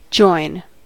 join: Wikimedia Commons US English Pronunciations
En-us-join.WAV